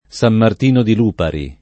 Sam mart&no di l2pari] (id.), San Martino di Venezze [Sam mart&no di ven%ZZe] (id.), San Martino in Converseto [Sam mart&no ij konverS%to] (E.-R.), San Martino Sinzano [Sam mart&no SinZ#no] (id.; antiq.